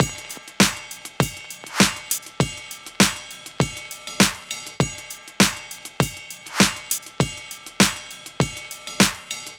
Unison Funk - 3 - 100bpm - Tops.wav